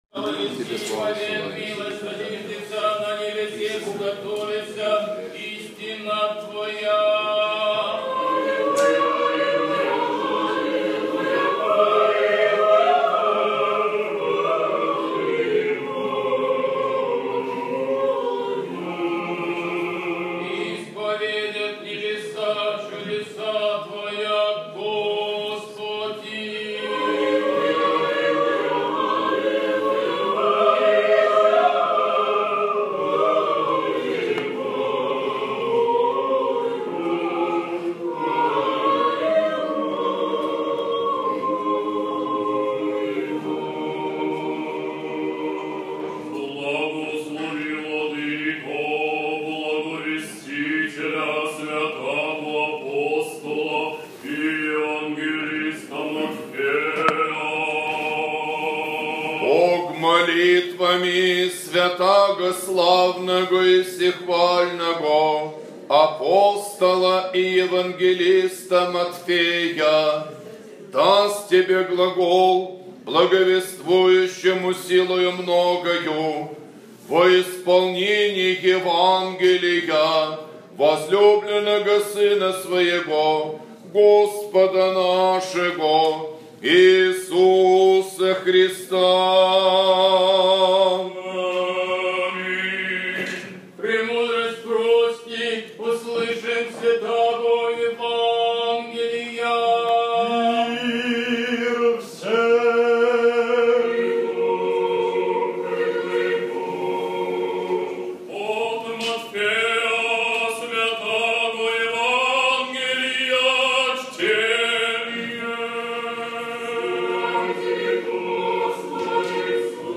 Фрагменты богослужения
Домой / Проповеди / Аудио-проповеди / 12 июля 2015 года.
Евангелие-12-7-15.mp3